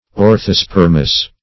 Search Result for " orthospermous" : The Collaborative International Dictionary of English v.0.48: Orthospermous \Or`tho*sper"mous\, a. [Ortho- + Gr.